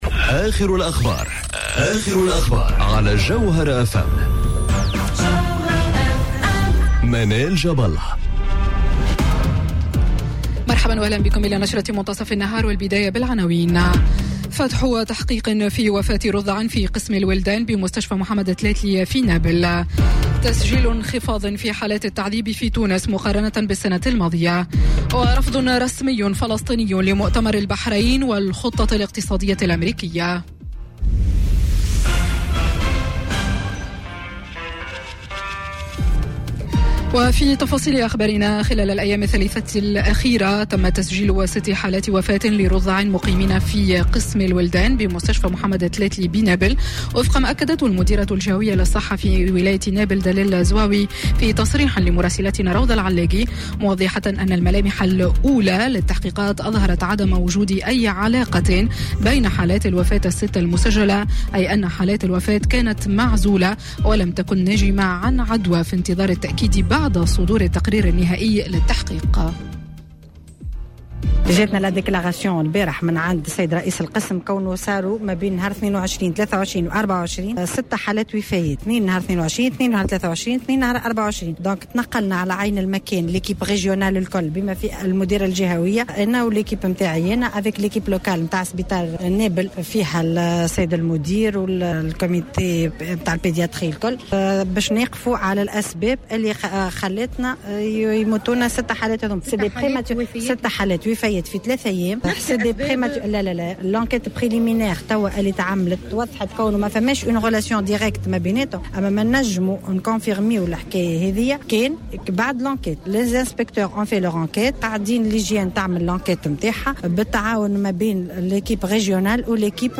نشرة أخبار منتصف النهار ليوم الثلاثاء 25 جوان 2019